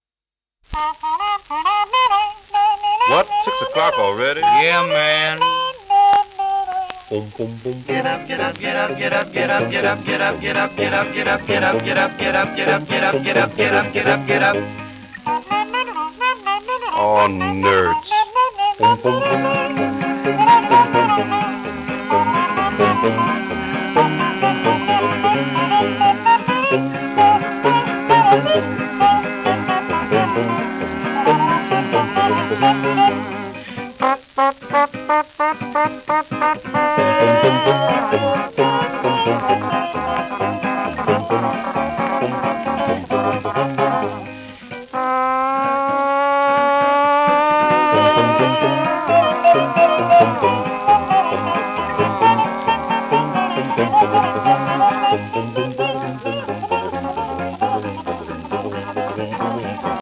盤質:B/B+ *やや溝あれ、キズ、スレ
1936頃の録音
ギター以外は全て人の声によるもの